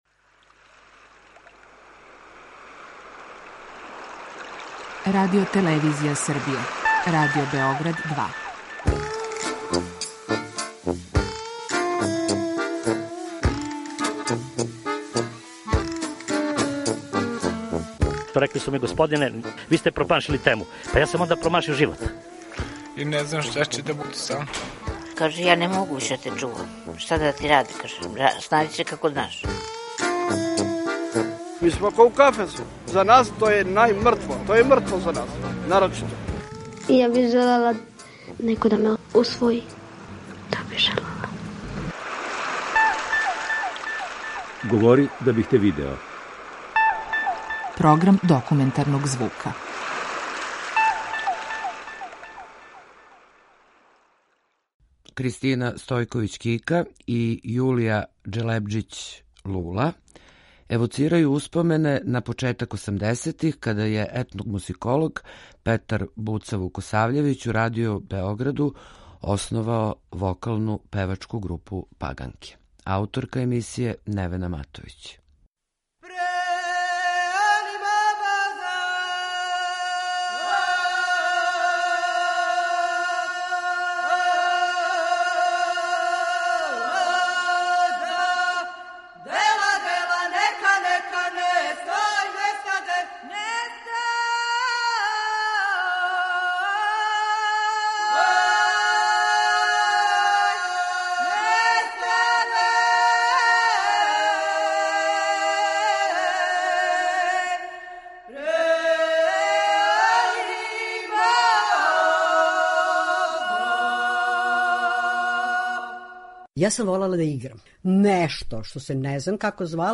Документарни програм
*У емисији сте слушали снимке који се чувају у Тонском архиву Радио Београда